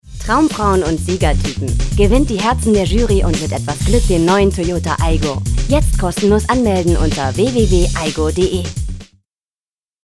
Sprecherin englisch (usa).
norddeutsch
Sprechprobe: Industrie (Muttersprache):